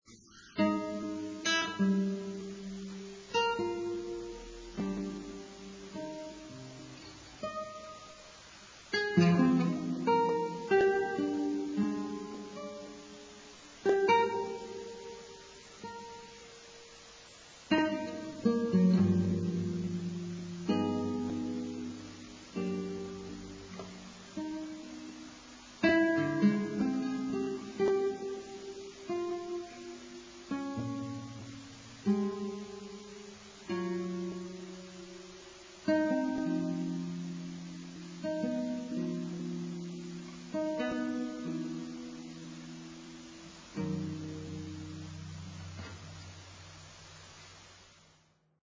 Improvisation aus Tönen
100k verrauschtes Morgenhaiku.